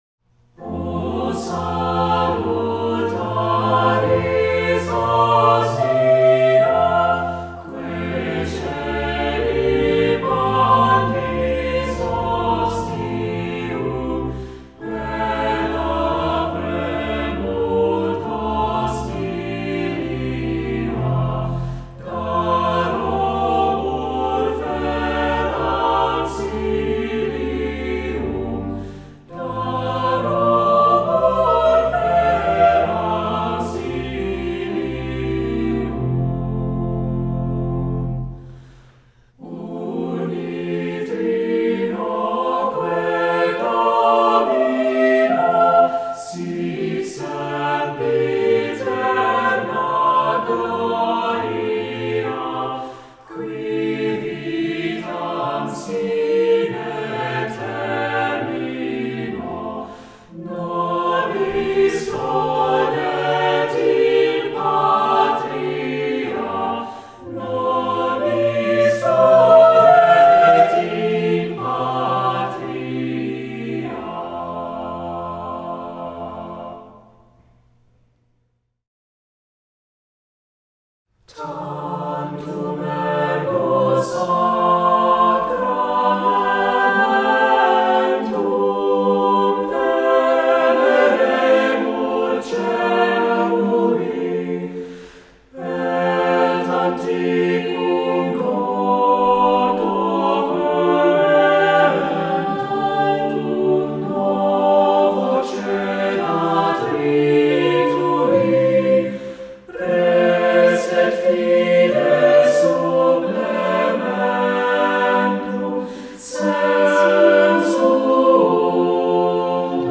Voicing: SATB,Cantor